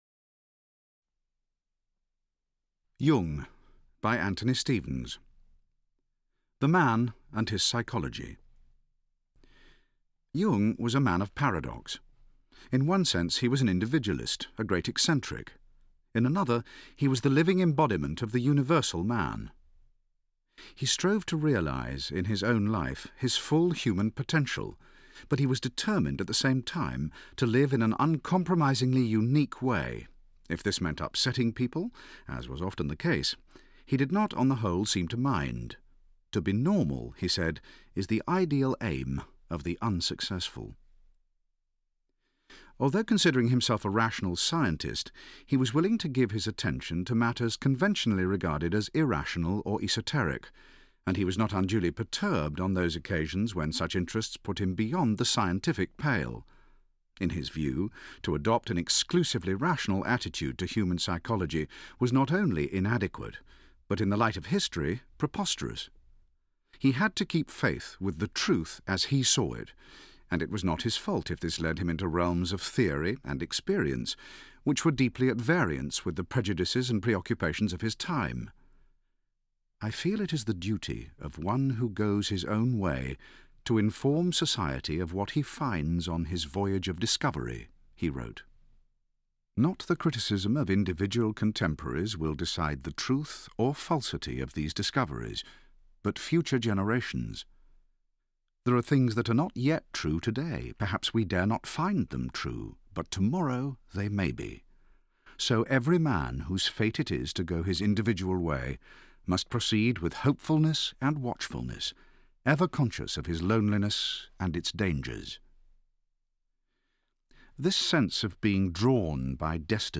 Audio kniha
Ukázka z knihy
• InterpretTim Pigott-Smith